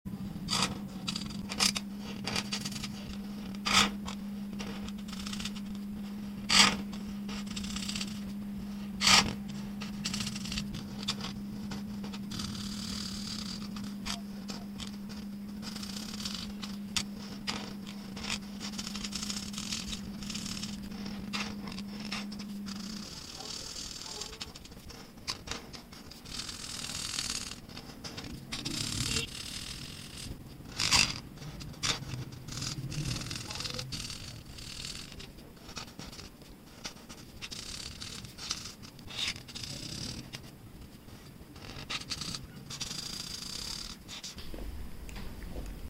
ASMR Colors: Coloring a Nail sound effects free download
Watch every smooth glide of color and enjoy the relaxing sounds that make ASMR art so addictive.